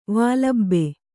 ♪ vālabbe